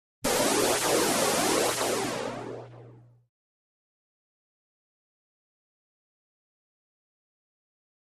Processed Air Release 1; [note; This Effect Has A Lot Of Out Of Phase Signal In It.],.